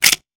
weapon_foley_pickup_24.wav